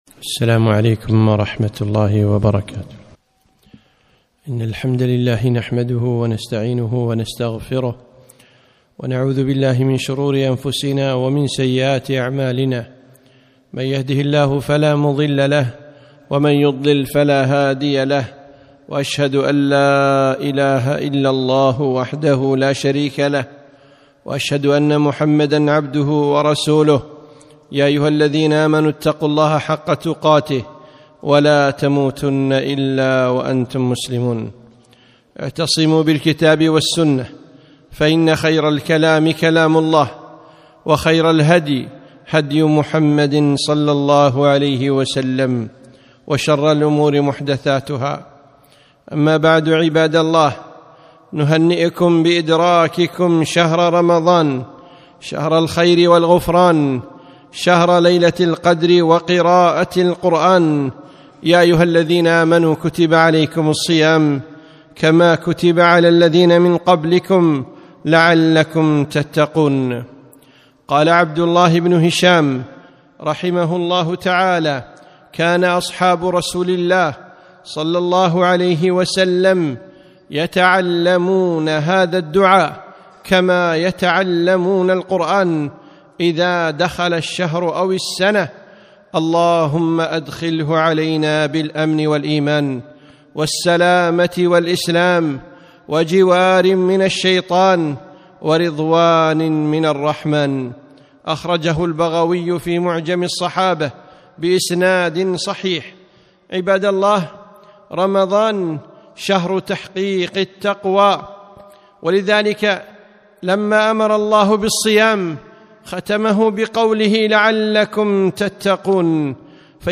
خطبة - شهر القرآن